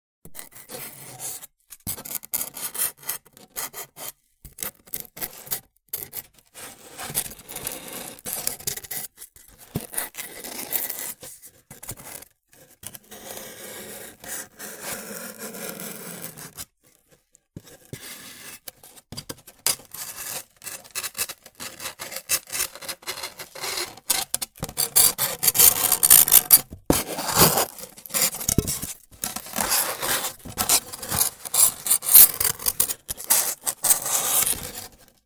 Звуки мизофонии
Вырезаем фигуры острым ножом в пенопласте